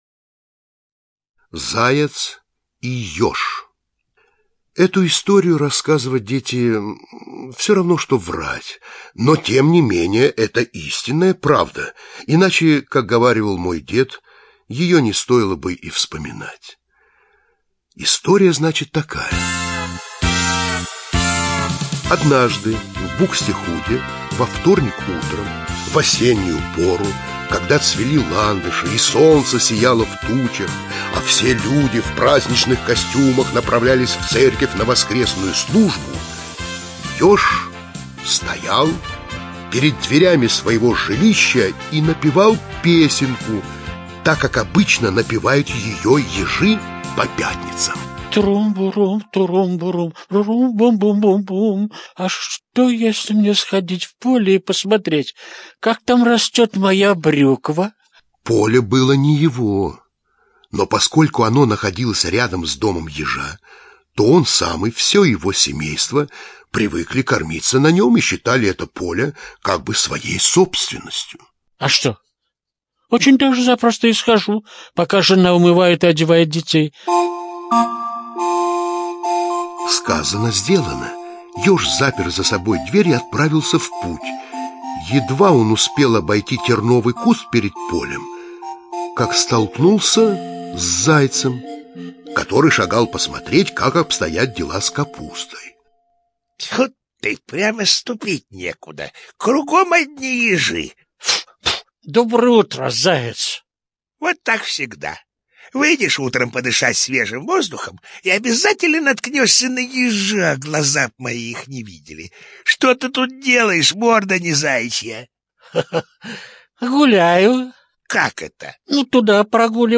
Заяц и ёж - аудиосказка братьев Гримм. Сказка о том, как Ёж проучил надменного и важного Зайца, презиравшего Ежа за коротенькие ножки.